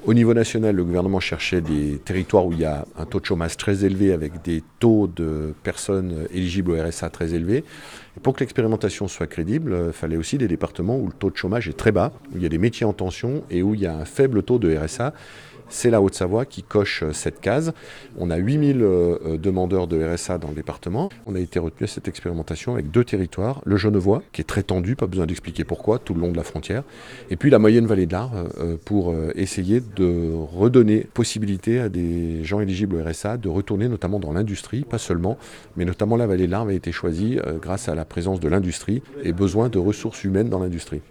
C’est la Haute-Savoie qui s'était portée candidate pour expérimenter ce dispositif, et le président du conseil départemental de la Haute-Savoie Martial Saddier explique pourquoi, alors que le taux de chômage est pourtant faible dans le 74.